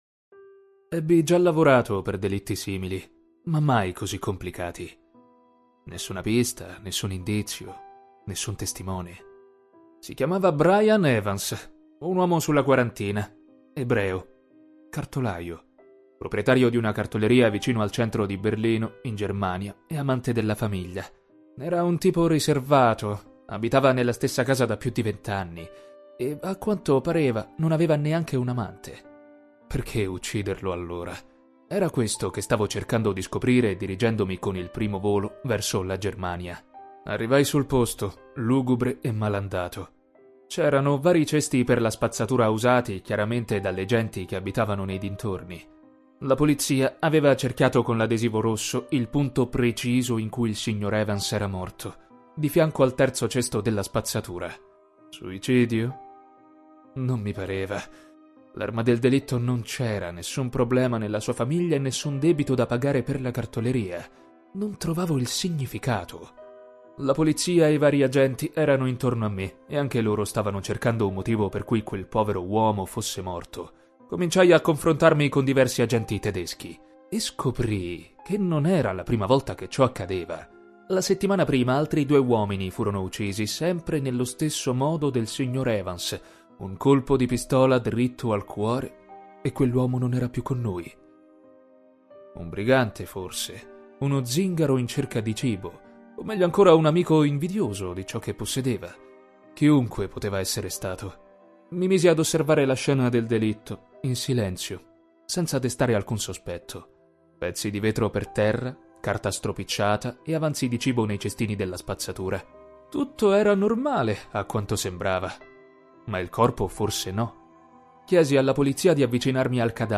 Narration
My voice it’s young, warm, and deep.
It also can be funny and speedy, or smooth, sexy, crazy, sad an so on.
RODE NT1A
Young Adult